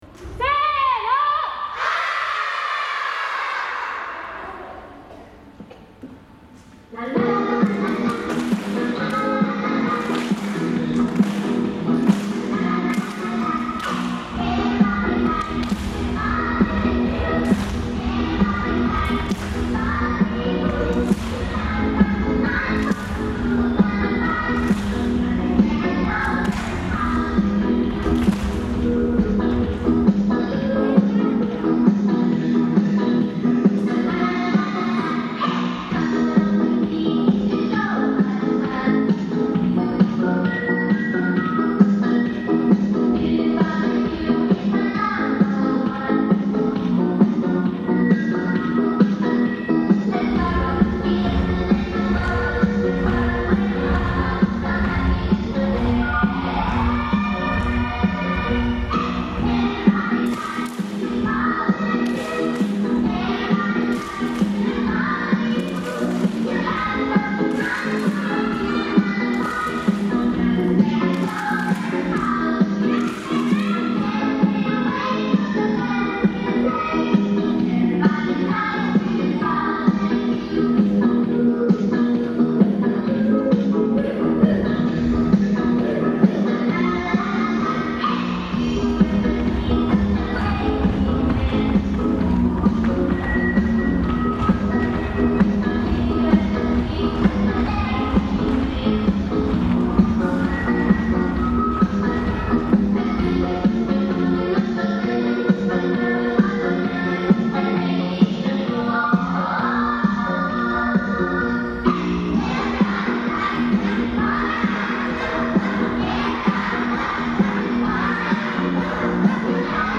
リーダーは講堂でこのHappy　Birthday　大空の司会、進行をし、自分たちの演奏は生中継します。
元気いっぱい全身を動かしながら、ペンギンやキリンなどの動物や首振り人形、太陽のリズム表現に挑戦します。